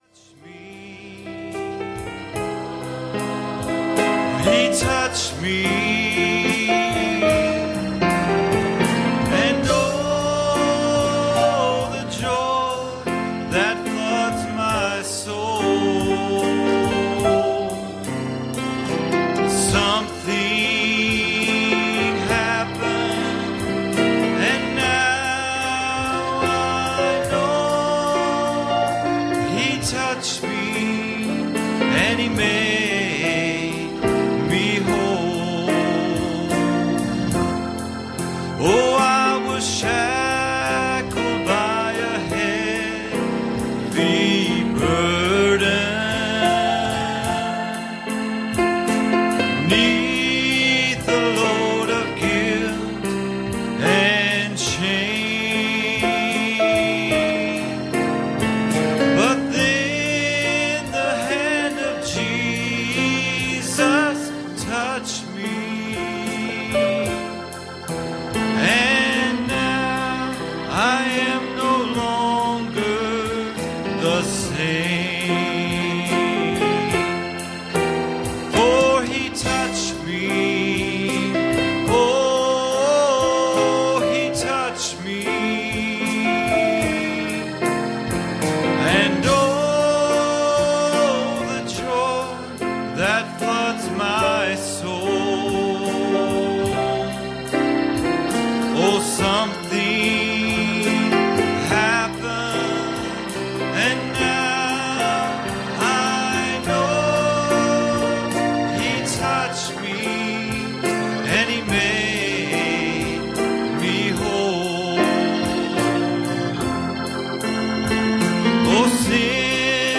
Passage: 2 Kings 4:2 Service Type: Wednesday Evening